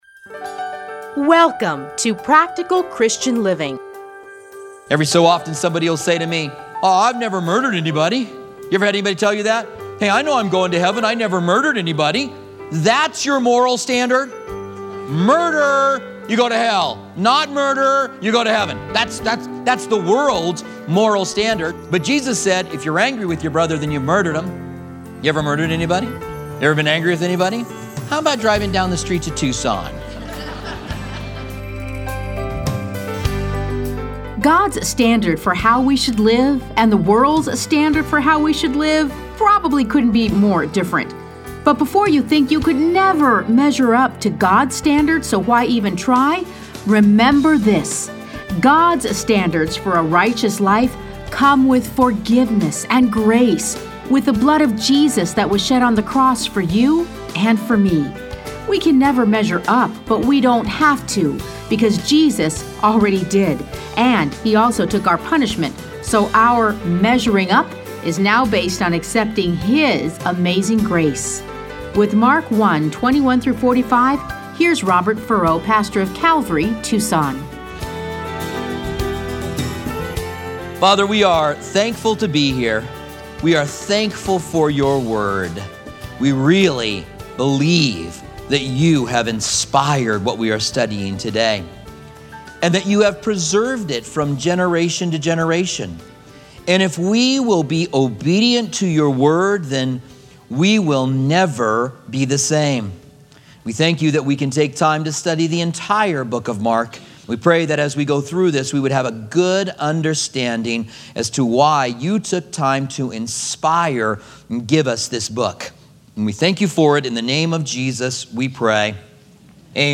Listen to a teaching from Mark 1:21-45.